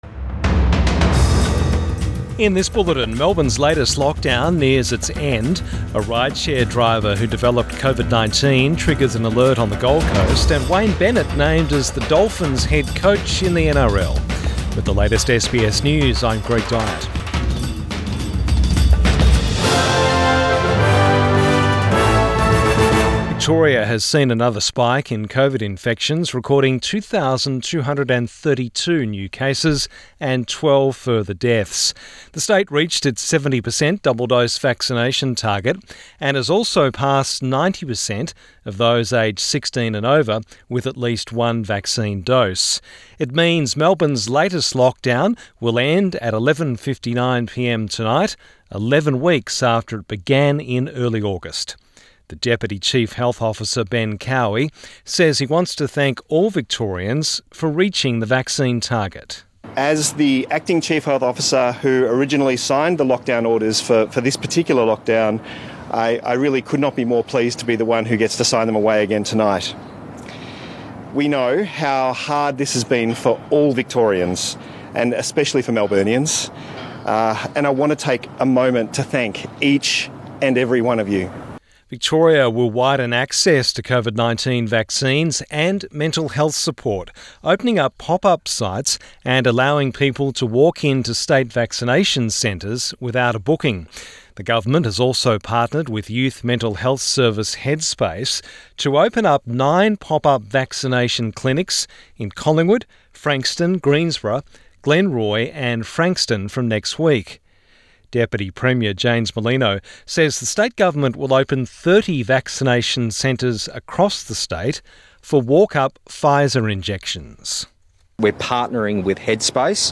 PM bulletin 21 October 2021